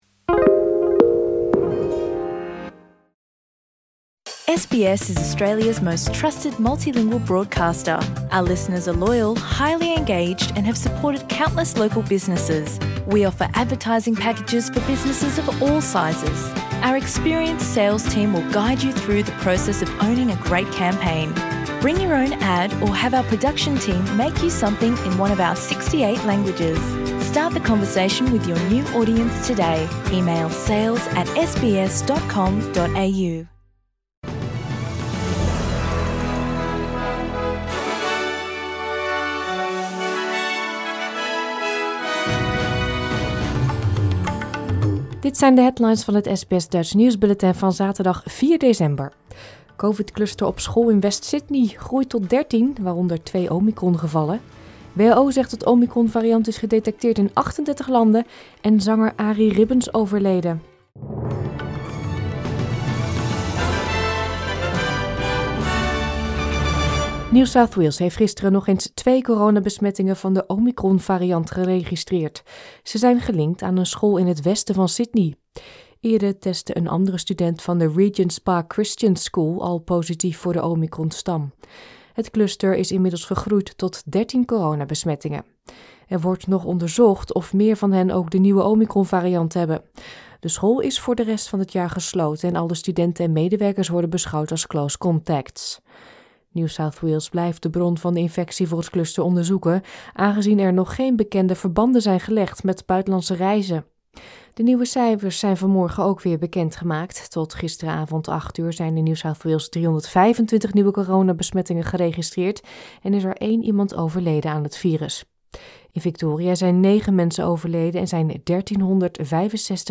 Nederlands / Australisch SBS Dutch nieuwsbulletin van zaterdag 4 december 2021